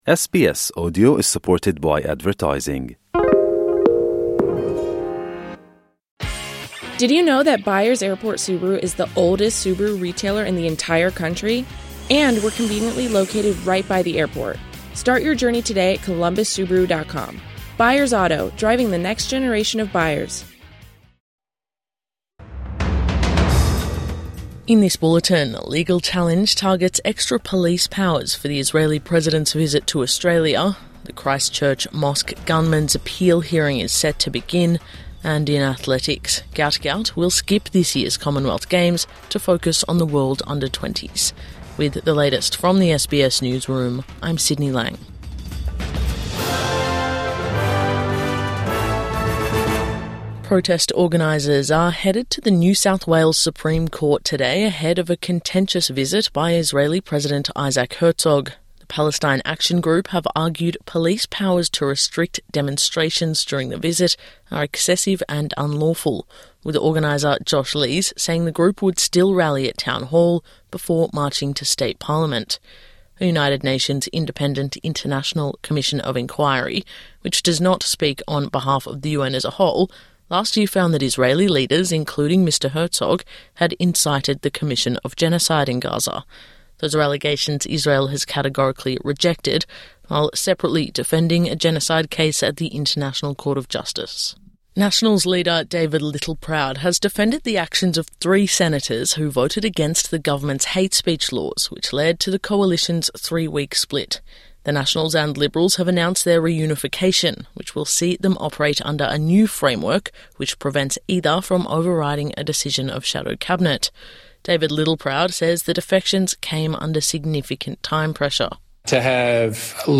Legal fight ahead of Israeli president visit | Morning News Bulletin 9 February 2026